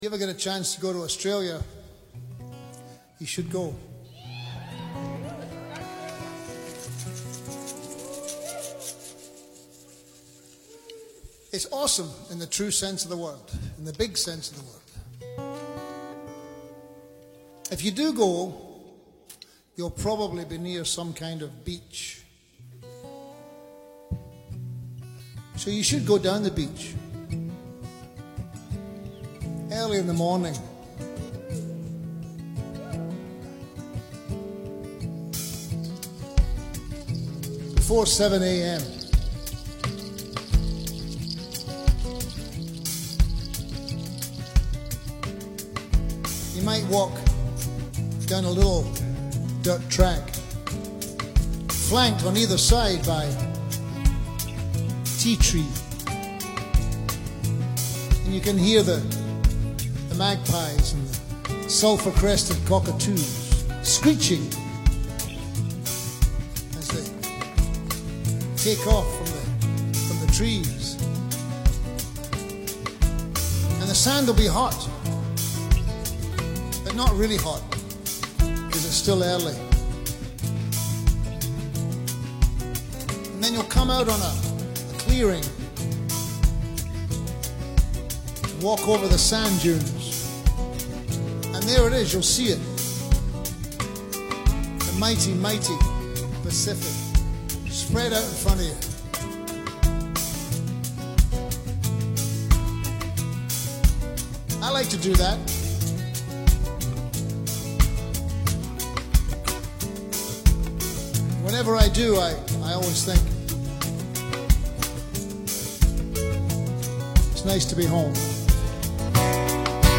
live concert series
Guitar
Bass
Drums
Keyboard, Saxophone
Percussion